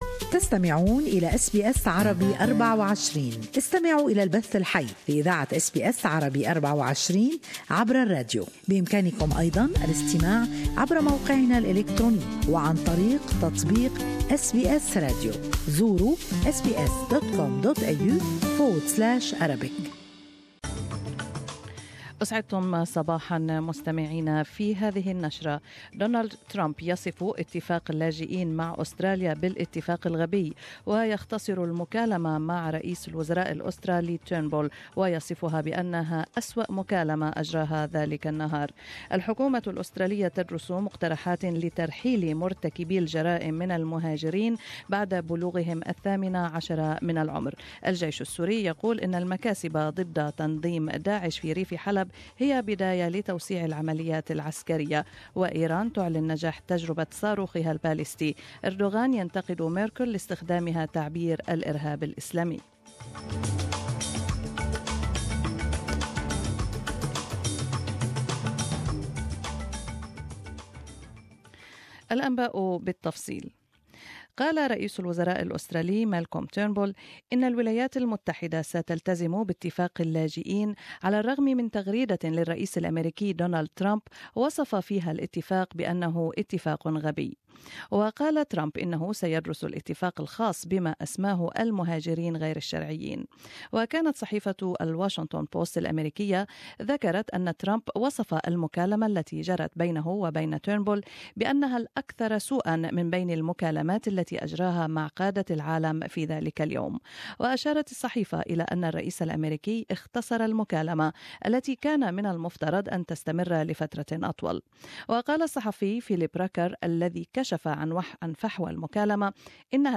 Arabic News Bulletin